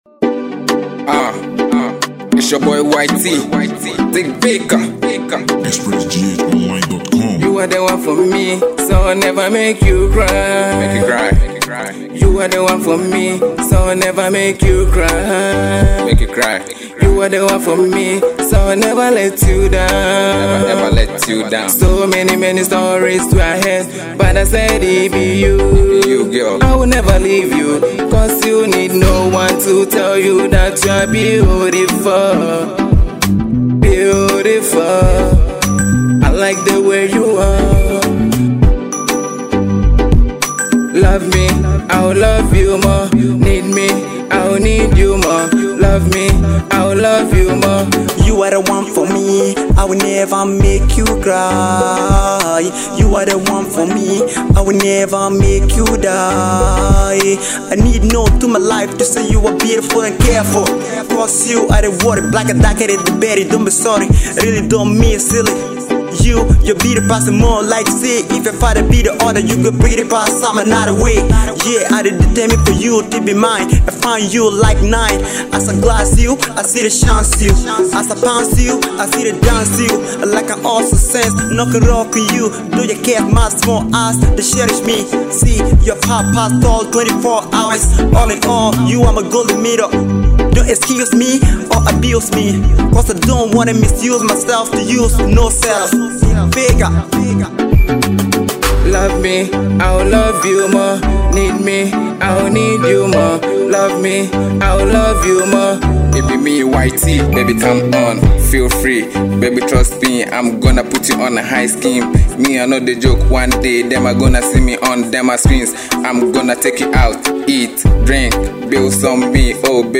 This is a massive tune you should never miss.